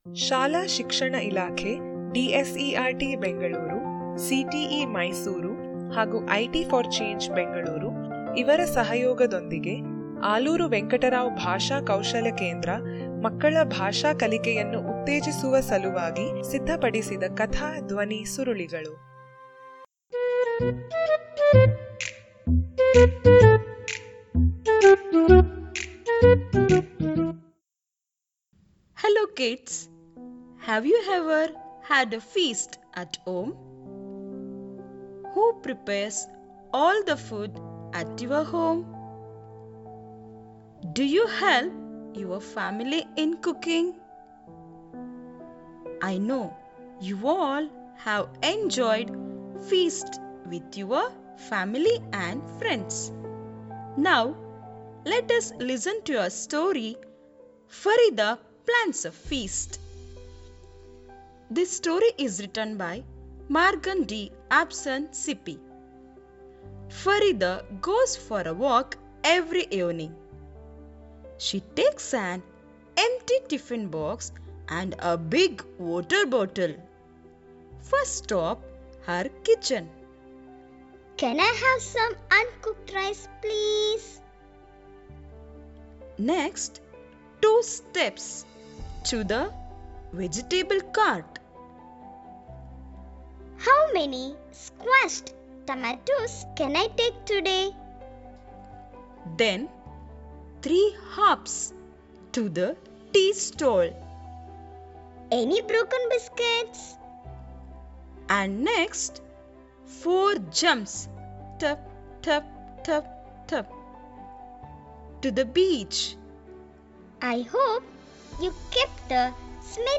Farida Plans A Feast - Audio Story Activity Page